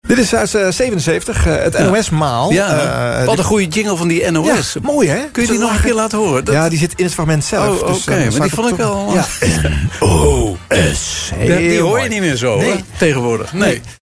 met een Shaft-achtig bedje eronder
in 1977 zo ver gekregen die jingle opnieuw in te spreken
opmerking van Frits Spits.